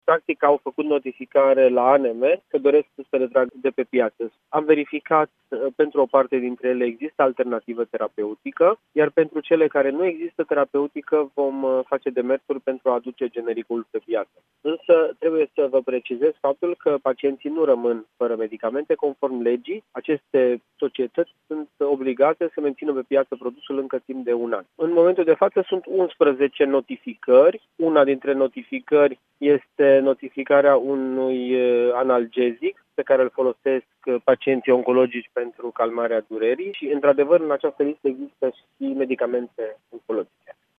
Însă, ministrul Florian Bodog a spus, la Europa FM, că aceste medicamente nu dispar de pe piață și că, pentru moment, pacienții nu vor rămâne fără tratament.